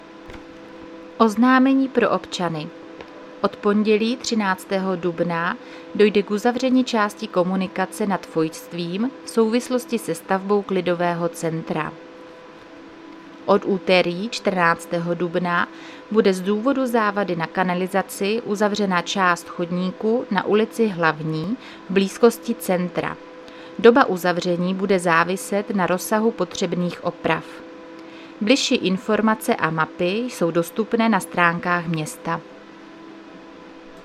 Záznam hlášení místního rozhlasu 10.4.2026